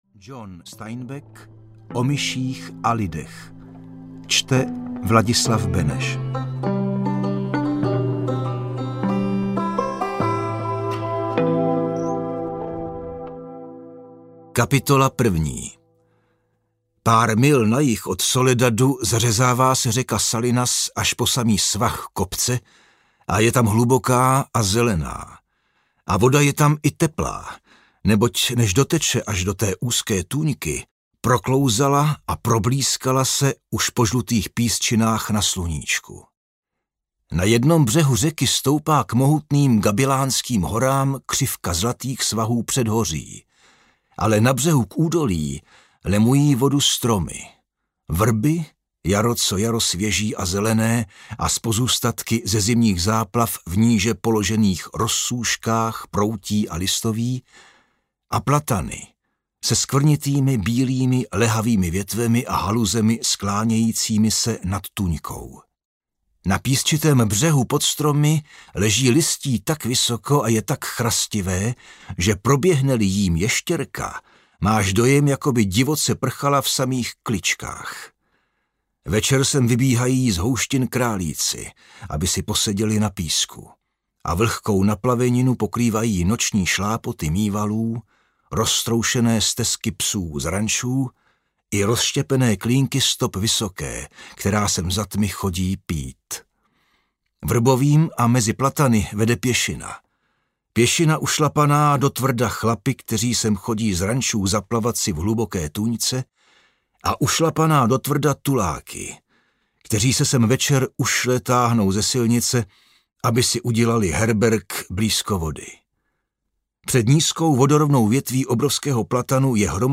O myších a lidech audiokniha
Poetická novela o síle přátelství, dnes již klasické dílo světové literatury, vychází v mistrném podání Vladislava Beneše.
o-mysich-a-lidech-audiokniha